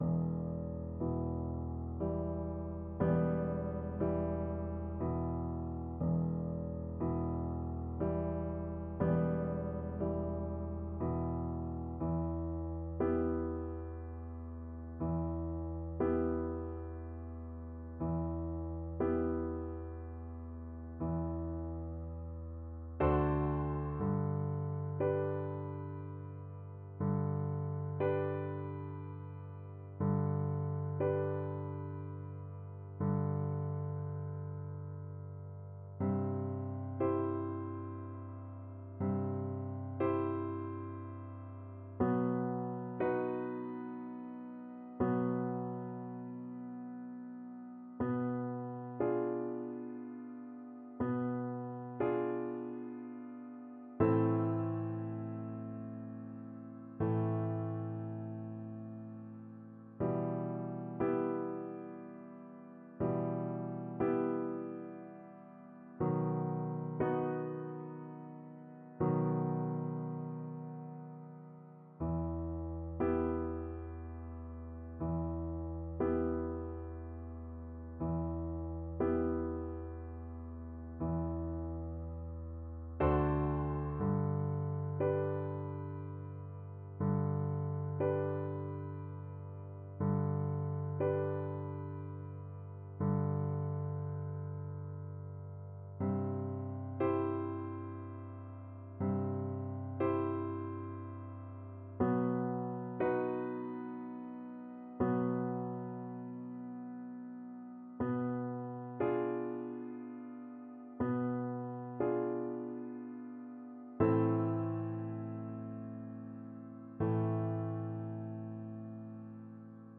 Classical Tárrega, Francisco Recuerdos de la Alhambra Violin version
ViolinPiano
E minor (Sounding Pitch) (View more E minor Music for Violin )
3/4 (View more 3/4 Music)
Andante
Classical (View more Classical Violin Music)